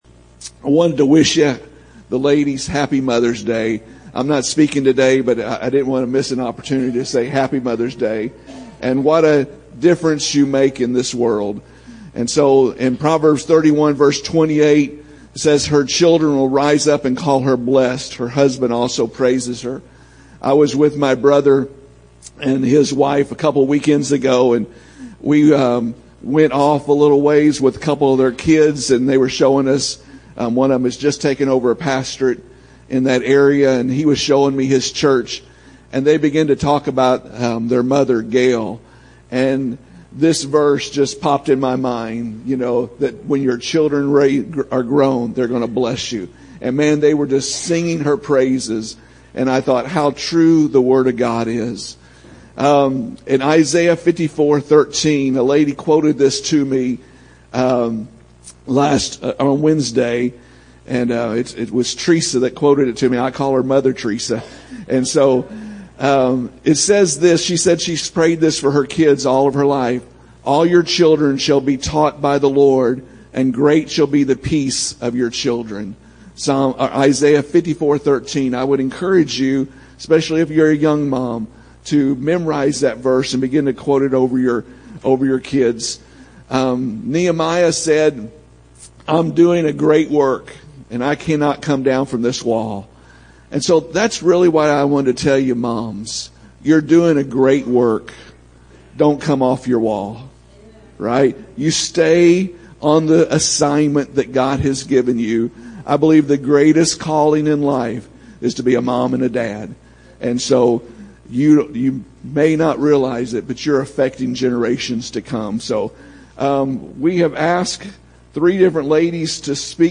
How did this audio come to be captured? Mother’s Day Service – May 14 2023